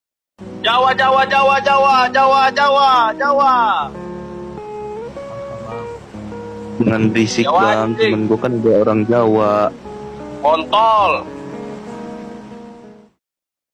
Sound Effect – Jawa Jawa Jawa…
Genre: Efek suara
sound-effect-jawa-jawa-jawa.mp3